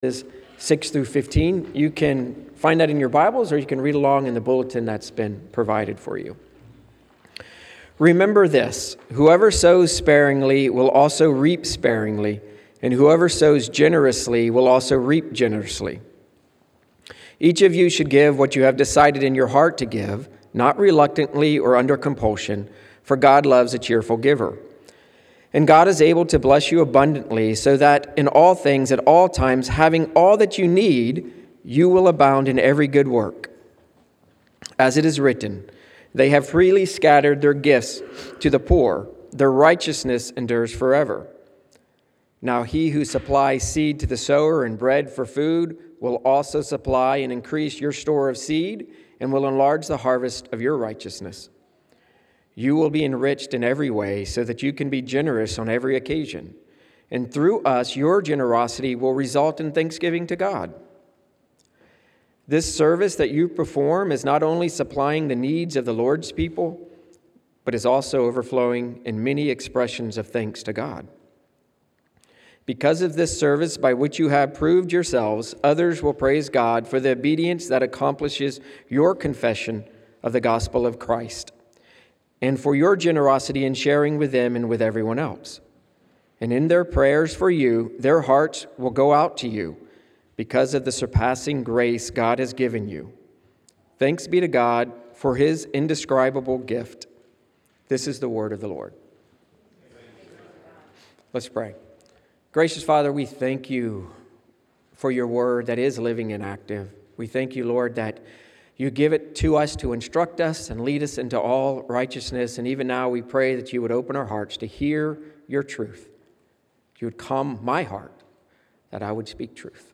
Sermons by the Pastors of Astoria Community Church